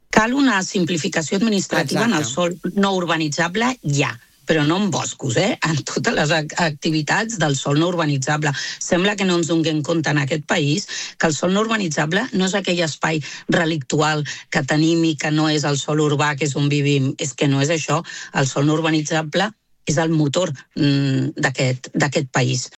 La consellera de Medi Natural ha aprofitat els micròfons de RCT per reclamar una simplificació administrativa en el sòl no urbanitzable que vagi més enllà de l’àmbit forestal.